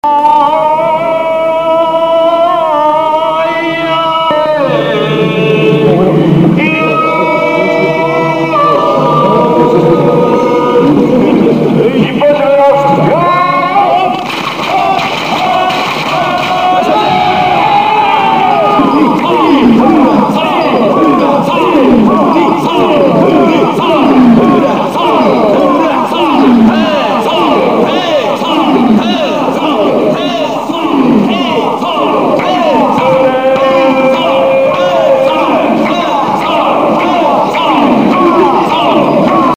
宮出　音声
mikosi onnsei.mp3